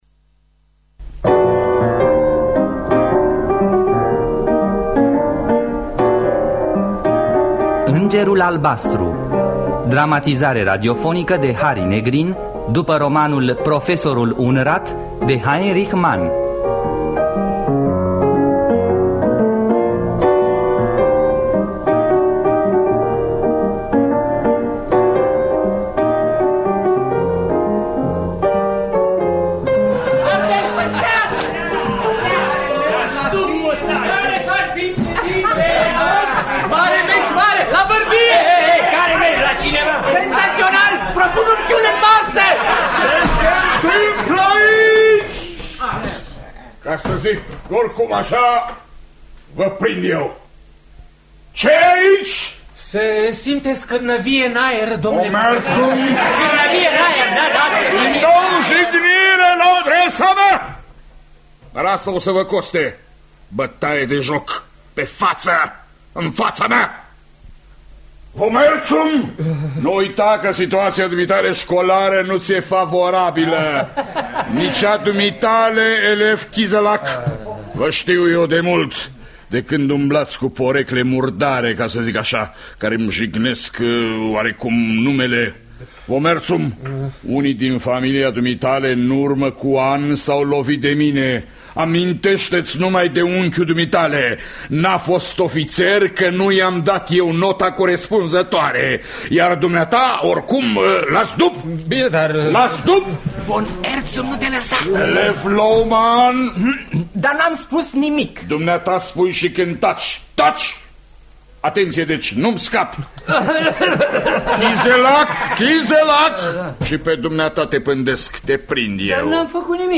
Dramatizarea radiofonică de Harry Negrin.